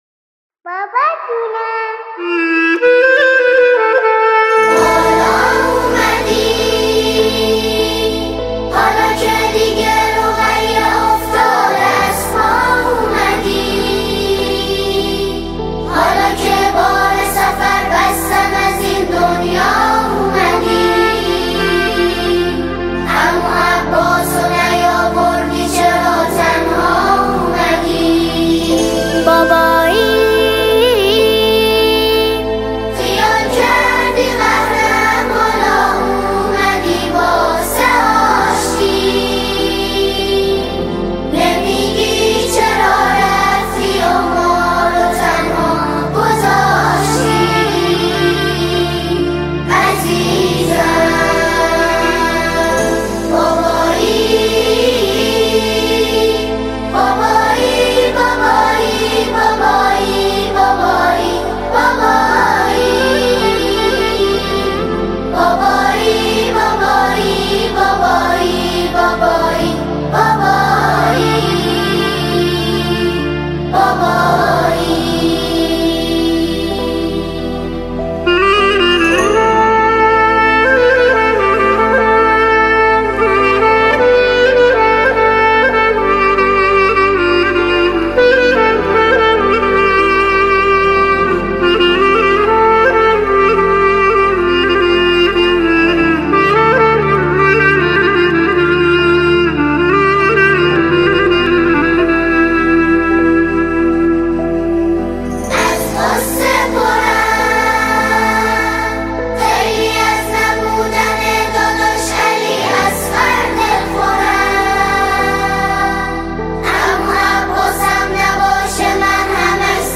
نماهنگ حزین